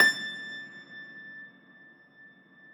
53e-pno21-A4.wav